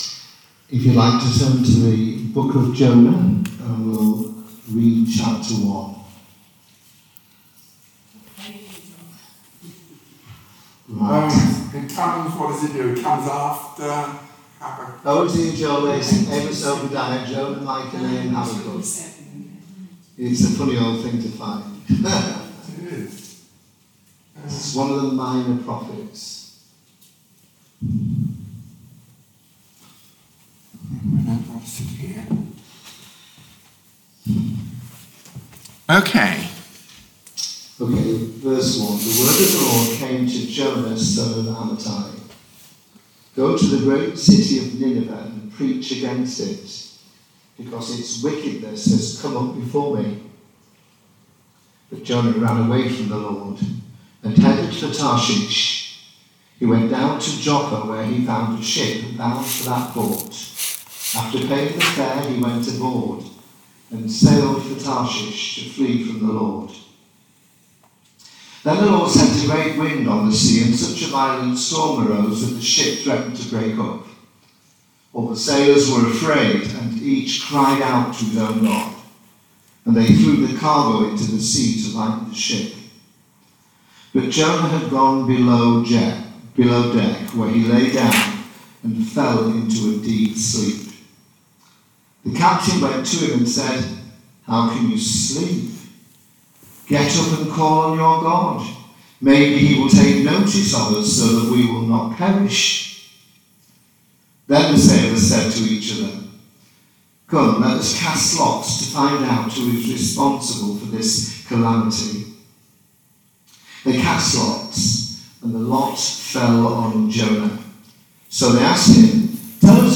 The Message: � Jonah Chapter 1 If you have a Bible to hand please open it at Jonah chapter 1 .